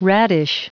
Prononciation du mot radish en anglais (fichier audio)
Prononciation du mot : radish